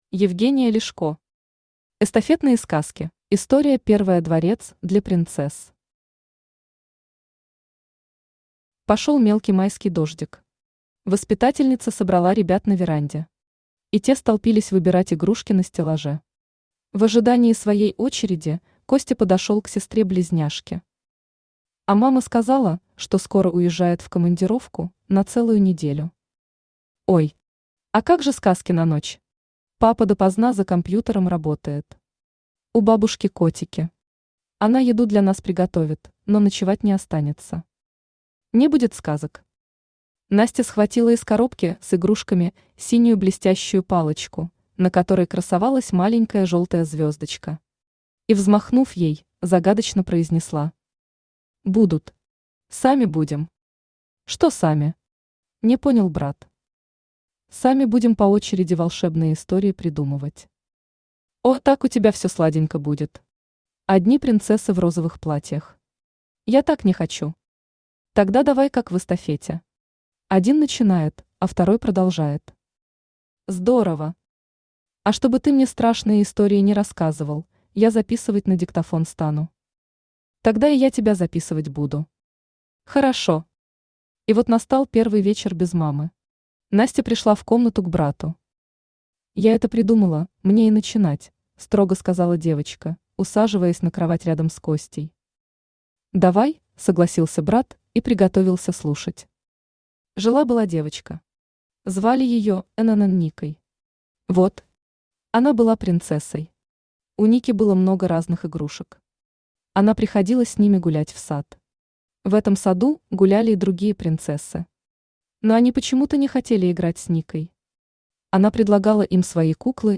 Аудиокнига Эстафетные сказки | Библиотека аудиокниг